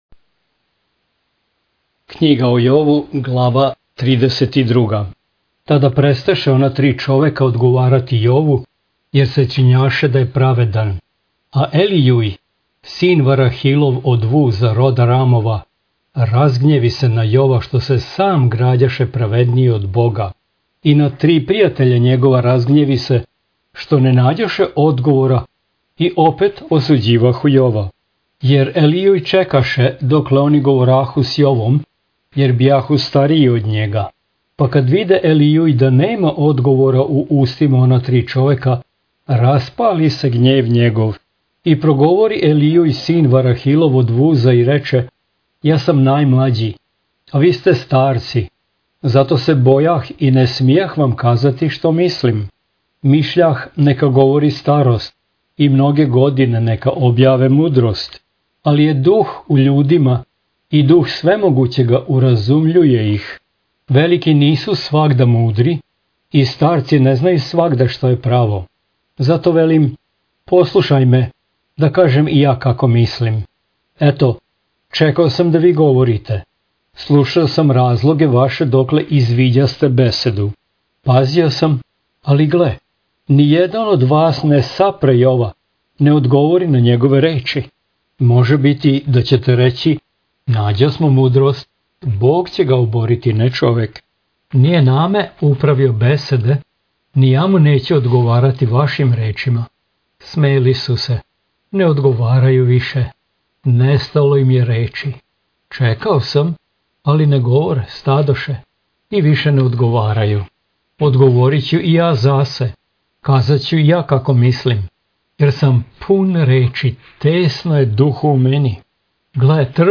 Jov / SVETO PISMO - čitanje - mp3